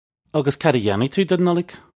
Pronunciation for how to say
Uggus kad uh yah-nee too dun Nullig? (U)
This is an approximate phonetic pronunciation of the phrase.